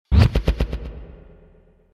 Короткий звук дежавю